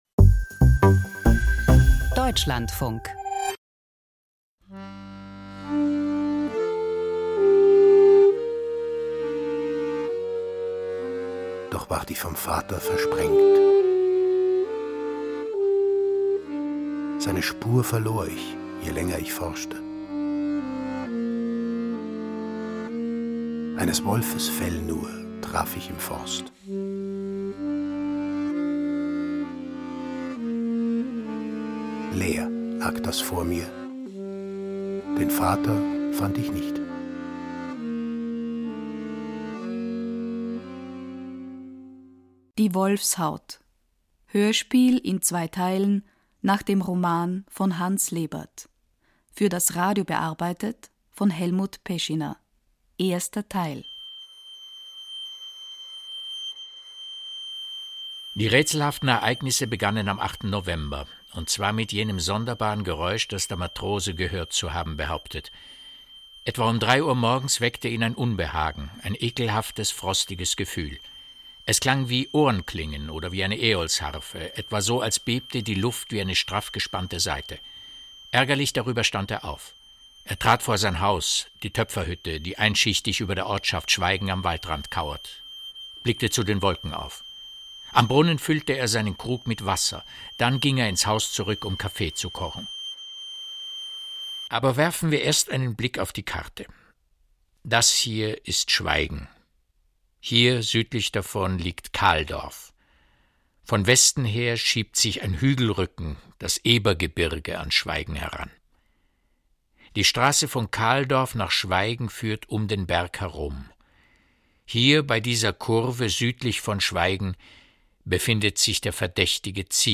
Die Wolfshaut (1) – Krimi-Hörspiel nach Hans Lebert
Preisgekröntes Hörspiel nach dem Roman von Hans Lebert.